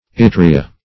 ittria - definition of ittria - synonyms, pronunciation, spelling from Free Dictionary Search Result for " ittria" : The Collaborative International Dictionary of English v.0.48: Ittria \It"tri*a\ ([i^]t"tr[i^]*[.a]), n. [NL.]